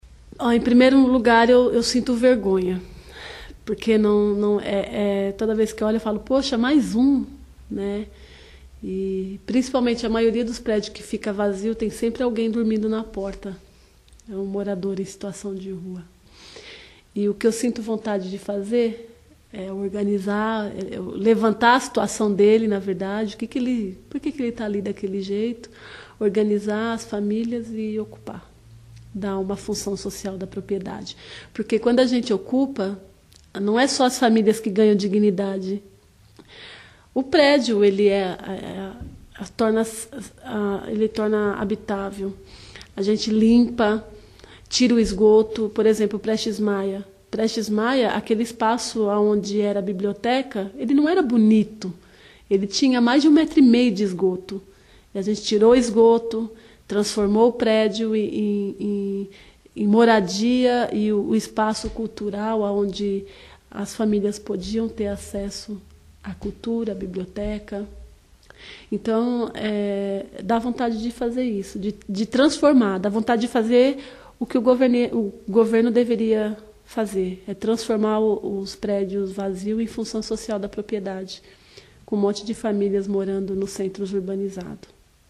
A ex-moradora de rua fala o que sente quando vê hoje um prédio vazio